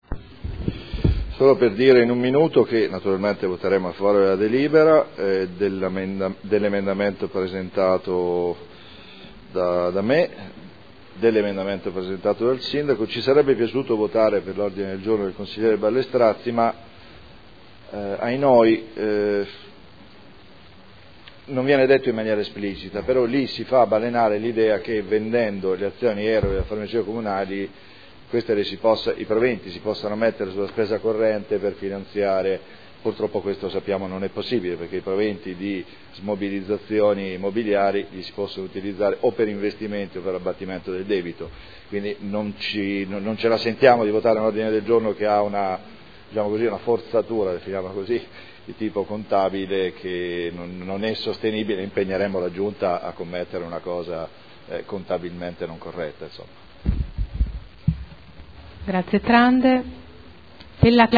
Paolo Trande — Sito Audio Consiglio Comunale
Seduta del 03/05/2012. Dichiarazione di voto su proposta di deliberazione, emendamenti e Ordine del Giorno sulle scuole d'infanzia comunali